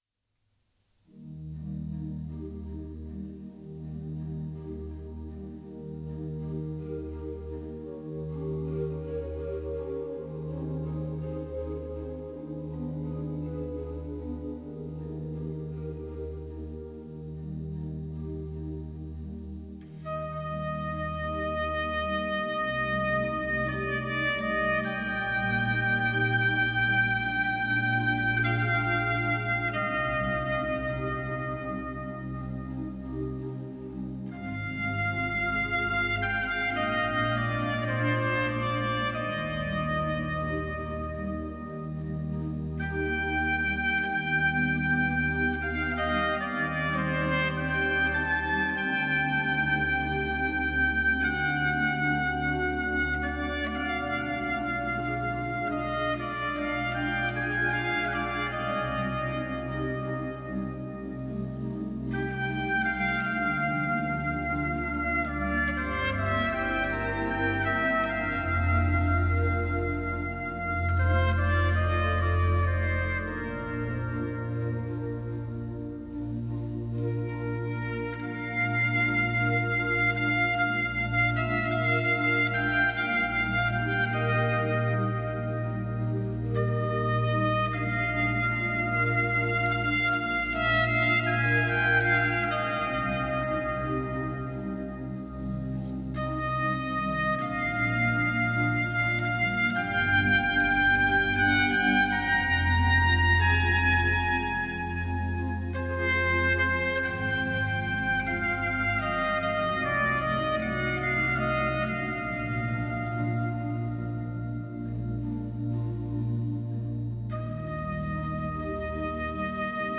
ORGANO y TROMPETA
Las más bellas obras para Trompeta y Órgano
grabadas en la Catedral Metropolitana de Valladolid y en otros lugares